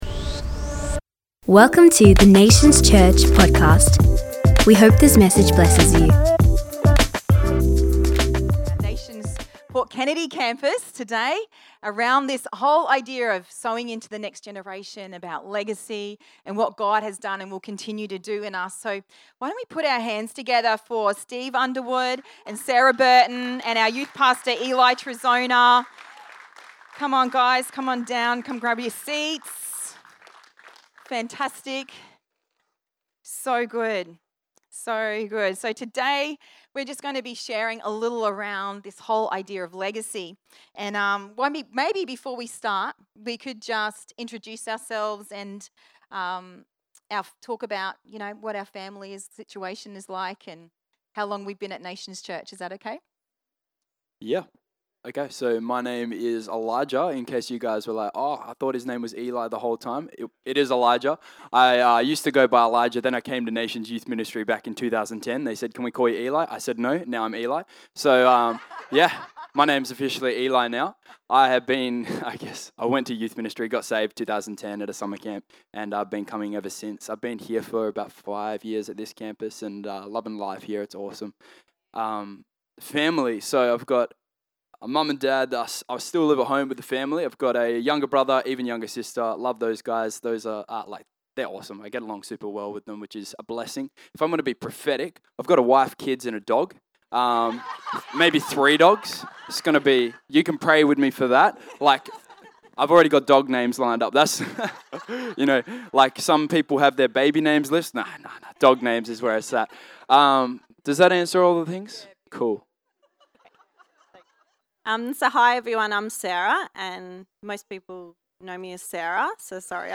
Generational Legacy: an inspirational discussion from Port Kennedy's 9am service.